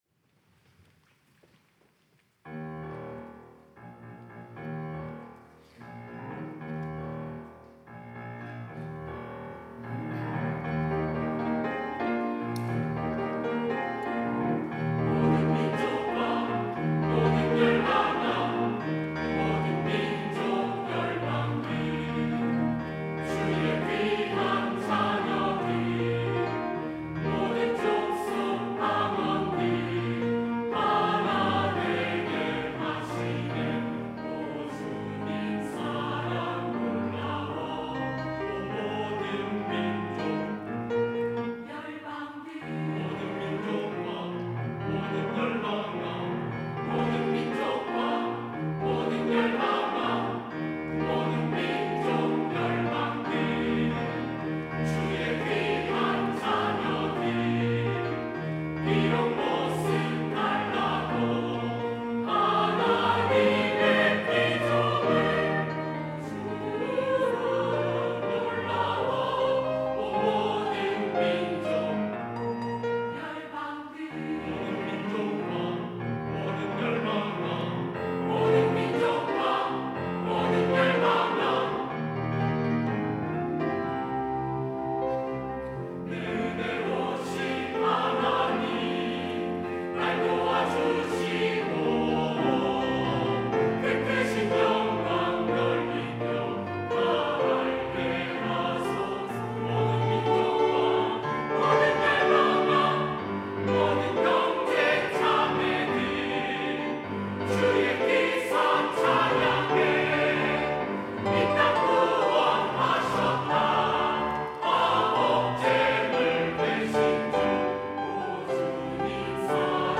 할렐루야(주일2부) - 모든 민족, 모든 열방
찬양대